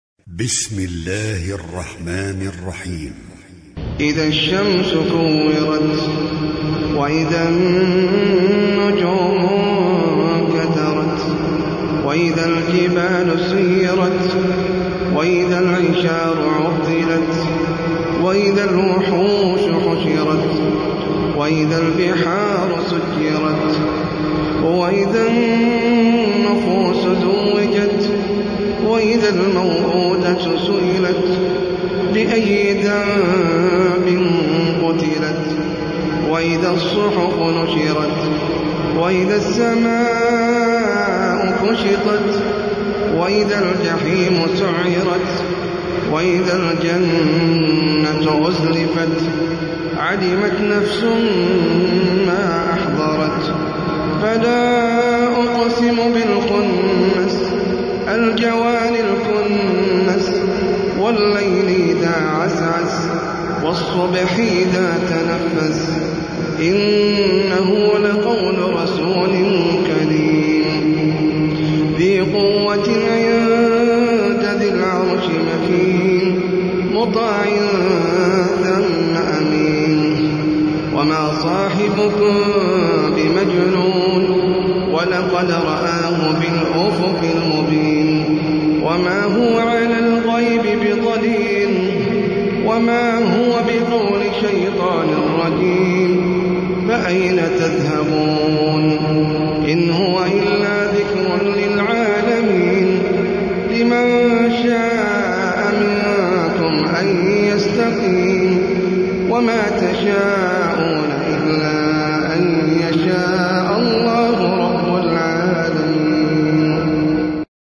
سورة التكوير - المصحف المرتل (برواية حفص عن عاصم
جودة عالية